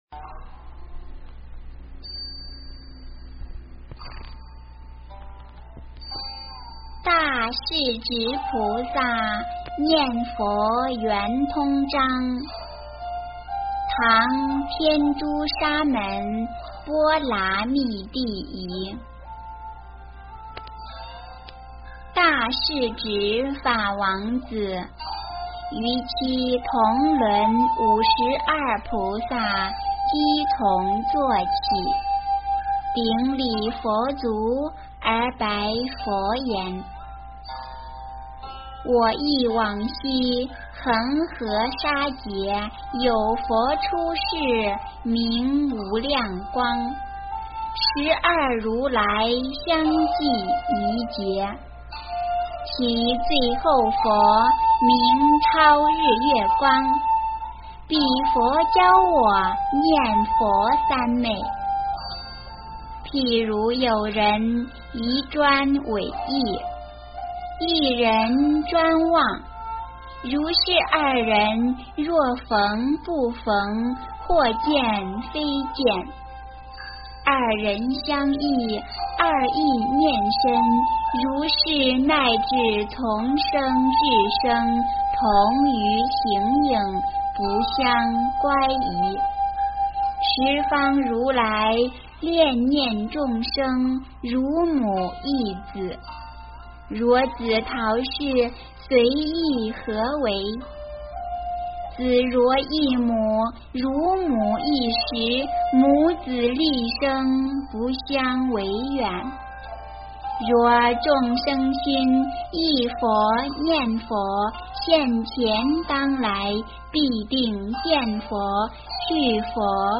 诵经
佛音 诵经 佛教音乐 返回列表 上一篇： 佛说阿弥陀三耶三佛萨楼佛檀过度人道经C 下一篇： 发菩提心经论卷下 相关文章 纯音乐-般若波罗蜜多心经--水晶佛乐 纯音乐-般若波罗蜜多心经--水晶佛乐...